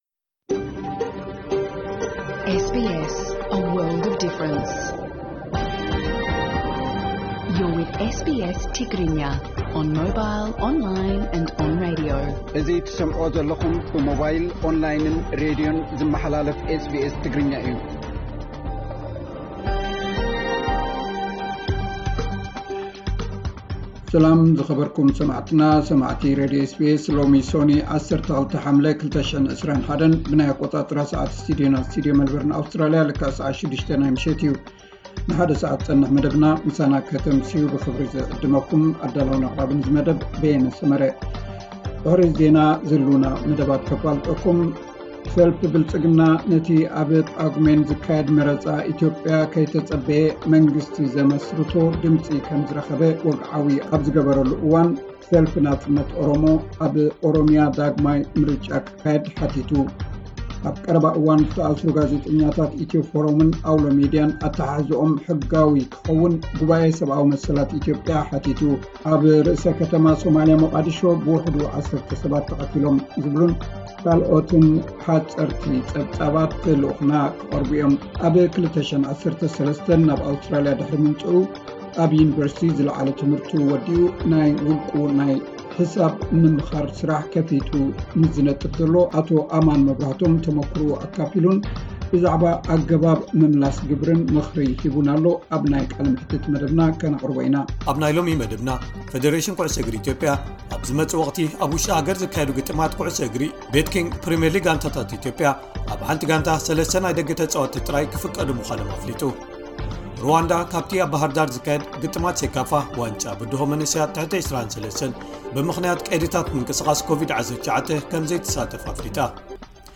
ዕለታዊ ዜና 7 ሓምለ 2021 SBS ትግርኛ